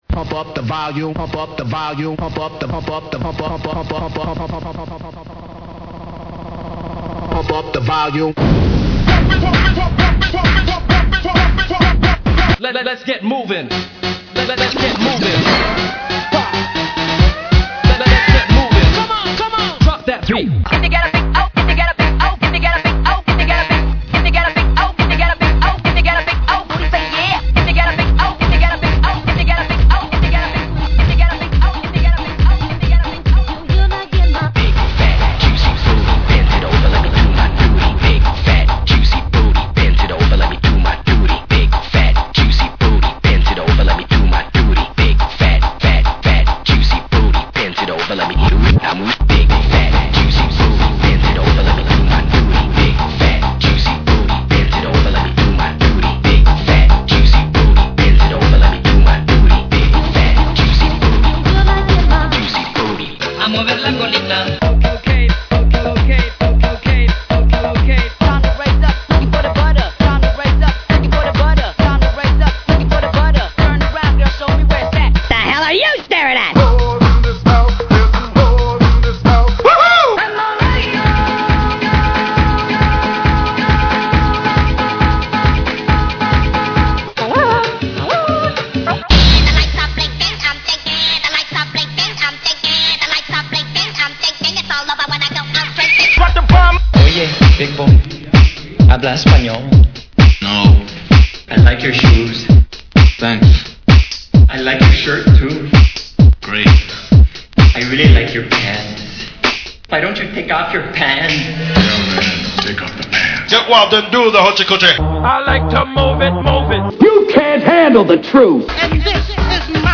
After playing around with various songs and song samples, I put together a few mix intros for the show.
(539k): 3rd Radio Intro Looking for the big ole...